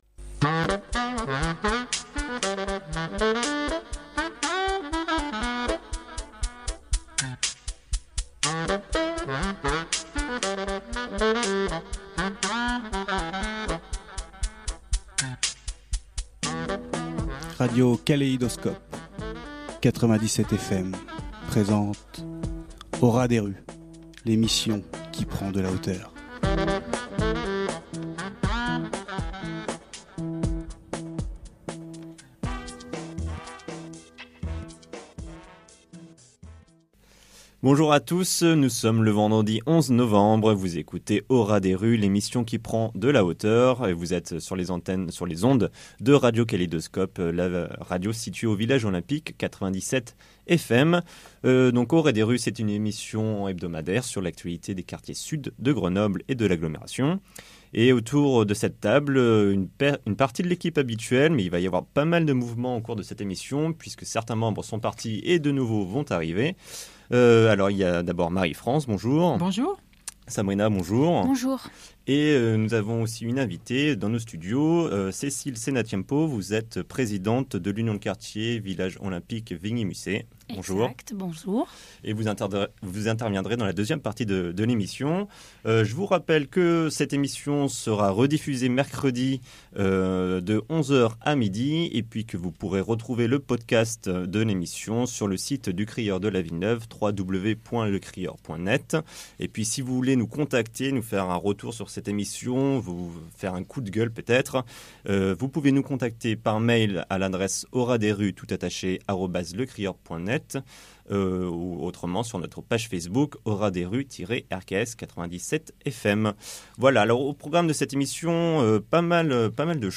Au ras des rues est une émission hebdomadaire sur Radio Kaléidoscope (97 fm) qui s’intéresse à l’actualité des quartiers sud de Grenoble et de l’agglo : Villeneuve, Village Olympique, Mistral, Abbaye-Jouhaux, Ville Neuve d’Échirolles…